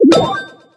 marig_turret_02.ogg